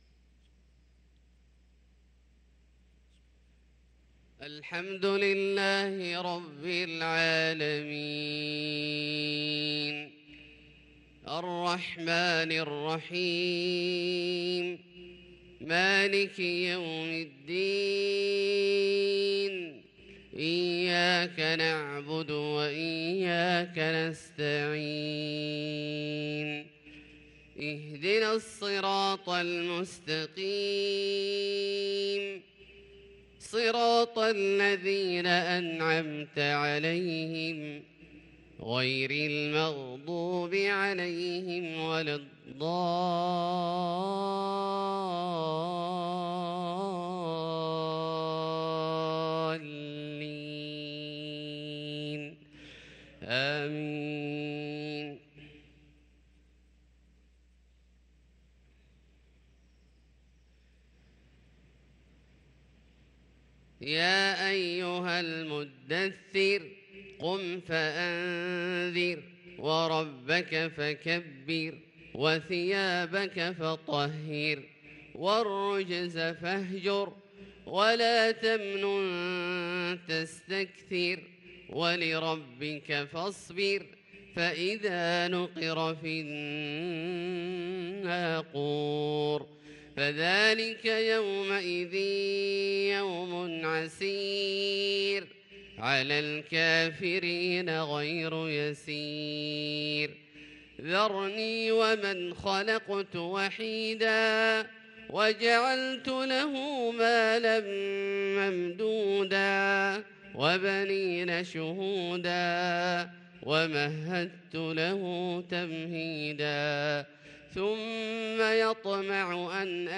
صلاة التراويح ليلة 11 رمضان 1443 للقارئ ياسر الدوسري - التسليمتان الأخيرتان صلاة التراويح